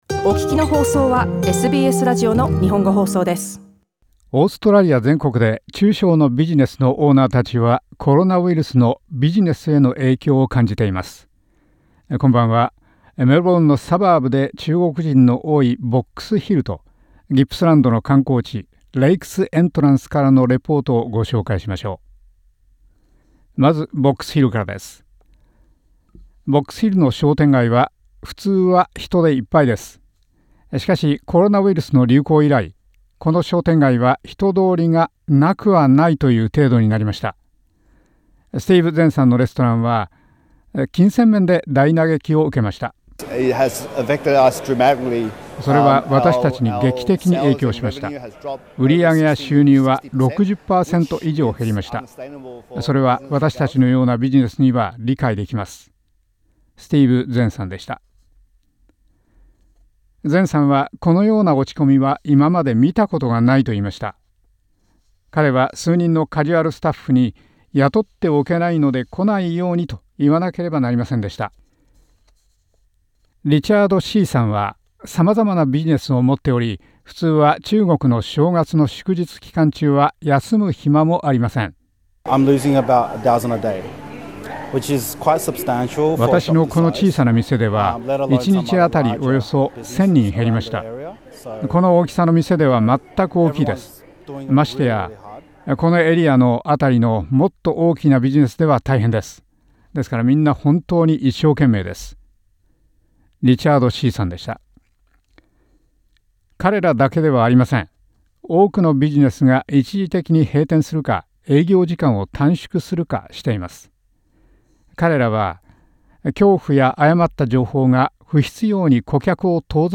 オーストラリア全国で中小のビジネスのオーナーたちは、コロナウイルスのビジネスへの影響を感じています。メルボルンのサバーブ、ボックスヒルと観光地、レイクス・エントランスからのレポートです。